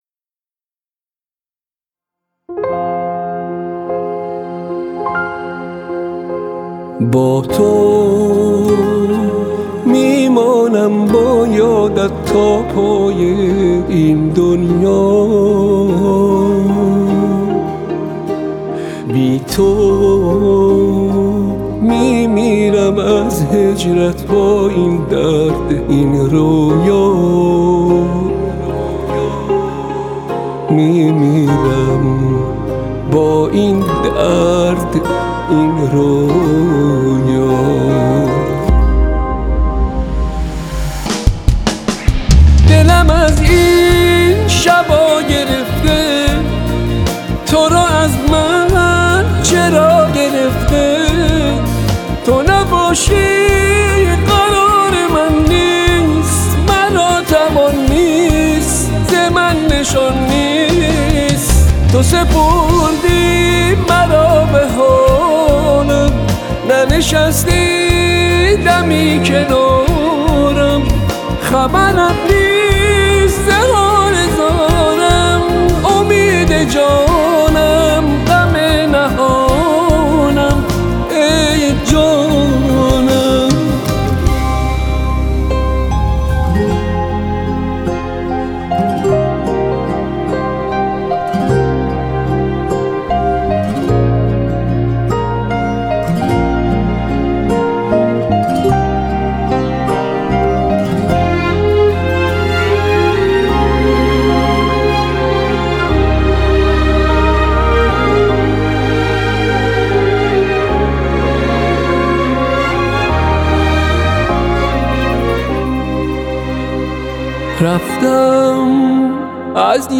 رپ
آهنگ با صدای زن
اهنگ ایرانی